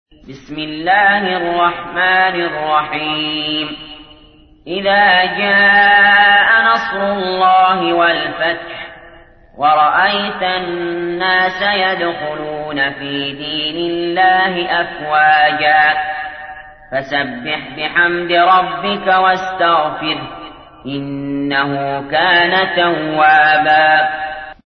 تحميل : 110. سورة النصر / القارئ علي جابر / القرآن الكريم / موقع يا حسين